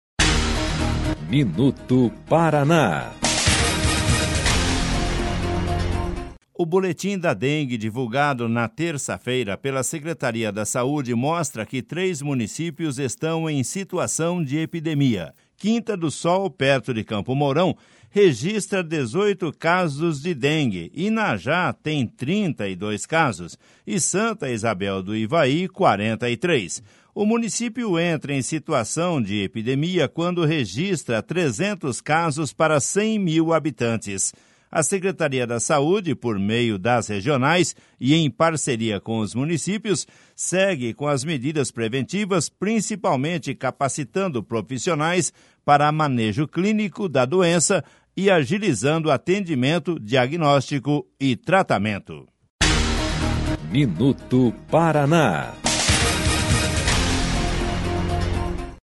MINUTO PARANÁ - BOLETIM SEMANAL DA DENGUE